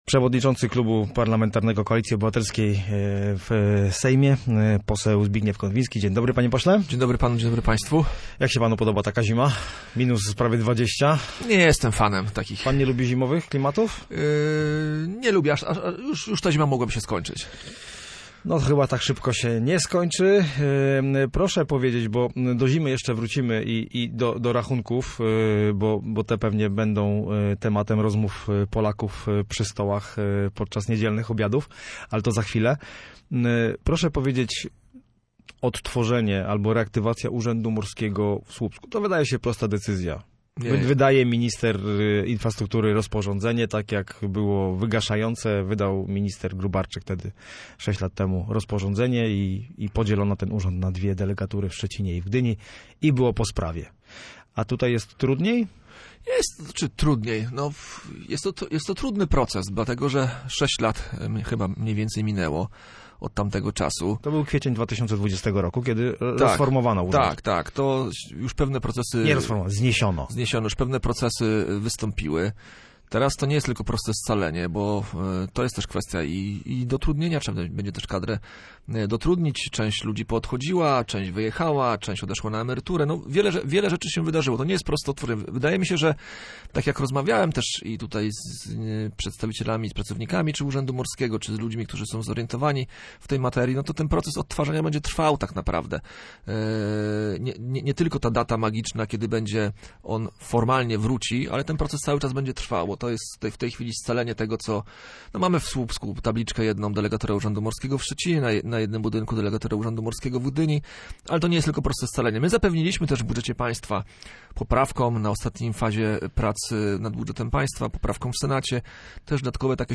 Poseł Zbigniew Konwiński był gościem Studia Słupsk.
Konwinski_gosc_OK.mp3